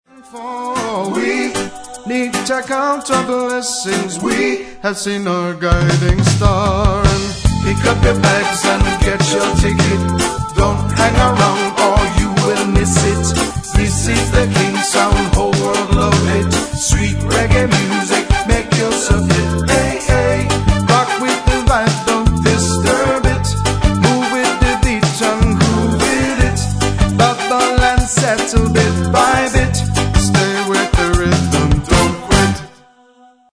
• Genre: Island rhythms, Jawaiian.
Fluctuating rhythms